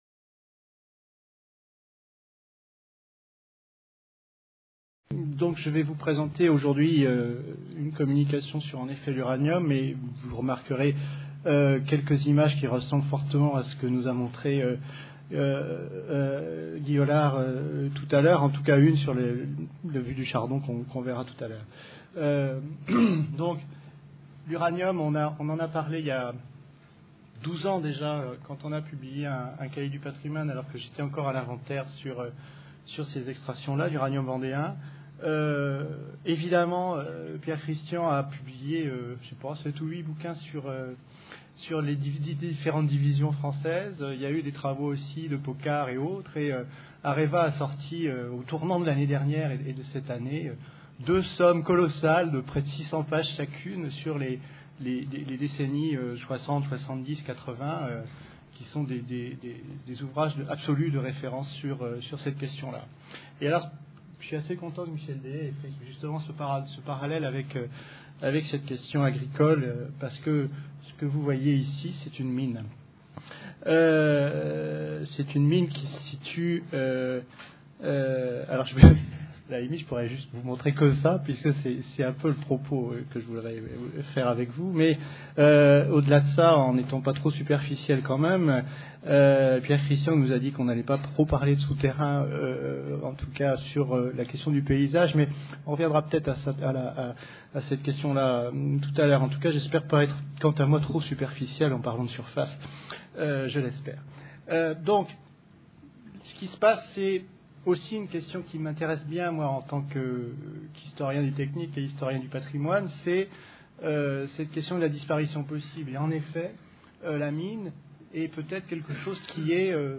Les paysages de la mine, un patrimoine contesté Ce colloque organisé par le CILA (Comité d’Information et de Liaison pour l’Archéologie, l’étude et la mise en valeur du patrimoine industriel) et le Centre Historique Minier s’adresse aux universitaires (historiens, géographes, etc.), responsables politiques, ingénieurs, industriels, aménageurs, urbanistes, architectes, paysagistes, acteurs du patrimoine, étudiants...